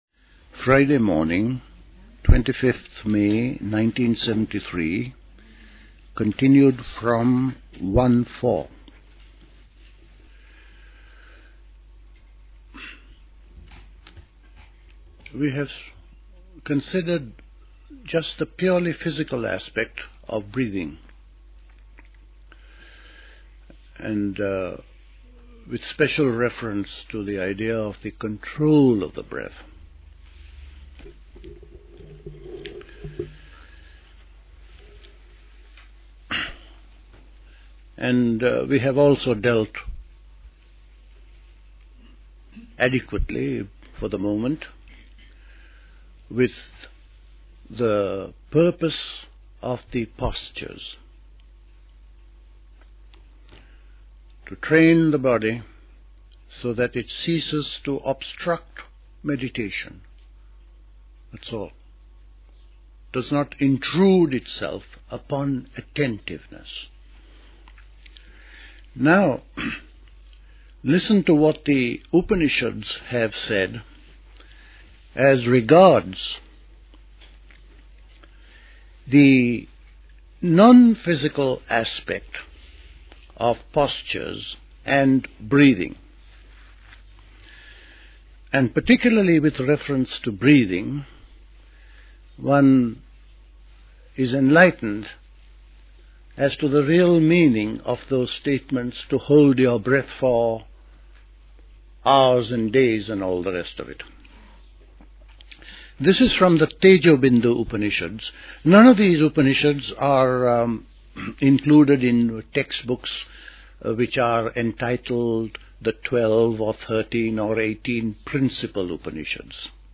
Recorded at the 1973 Catherington House Summer School.